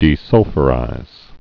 (dē-sŭlfə-rīz)